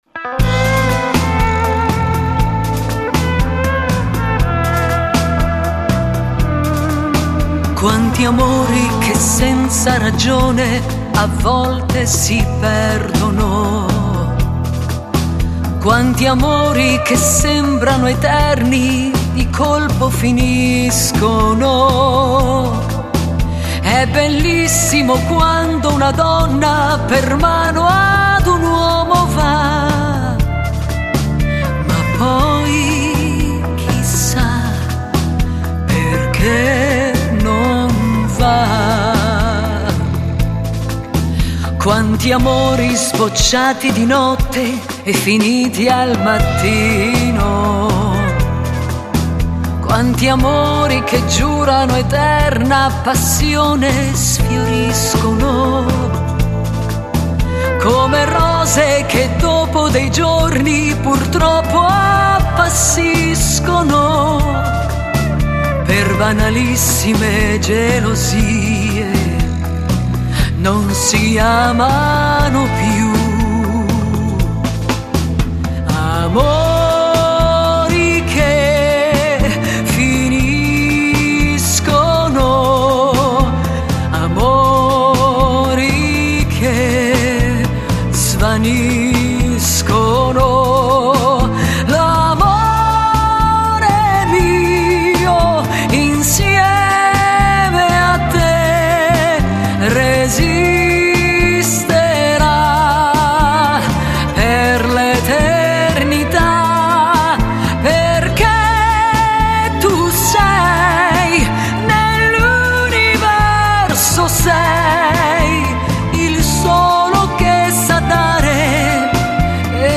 Genere: Beguine